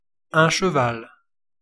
Ääntäminen
France: IPA: [ʃə.val]